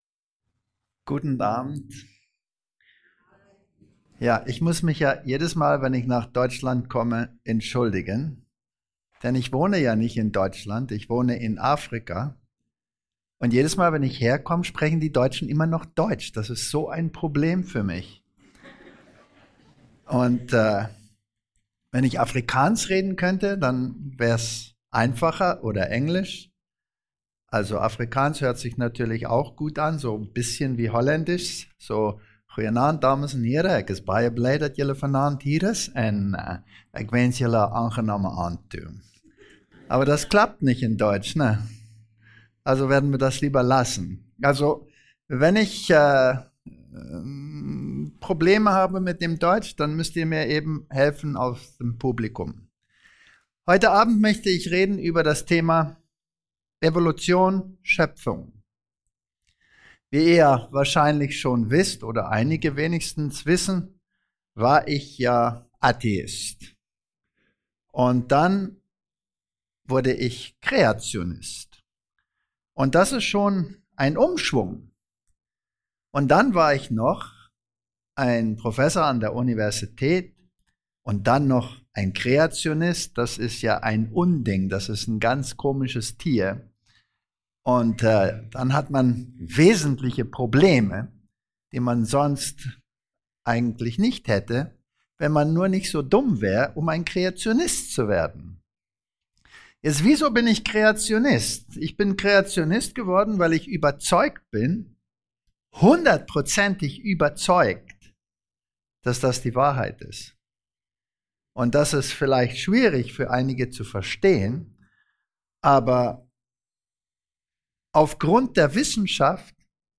Faszinierende Erklärungen eines Wissenschaftlers über Dornen und Disteln, Parasiten und Raubtiere, "überflüssige" Organe und vieles mehr.